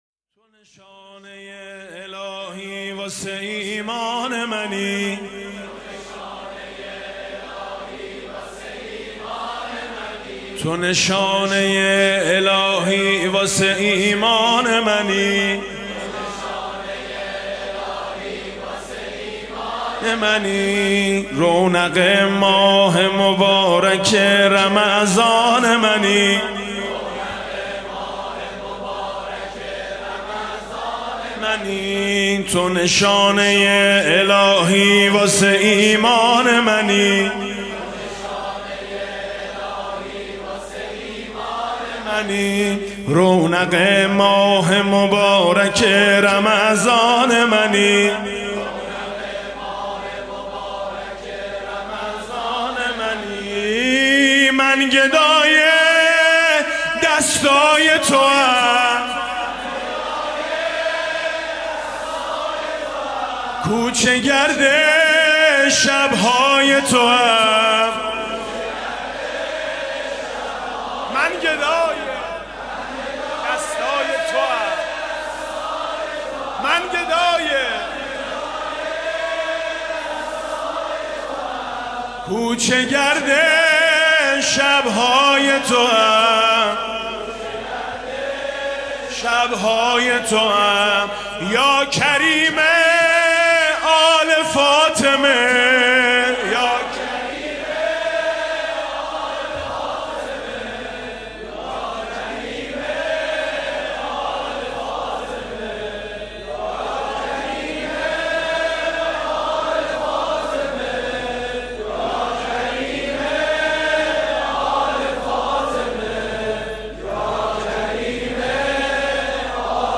شب پانزدهم رمضان 96 - هیئت شهدای گمنام - سرود - رونق ماه مبارک رمضان منی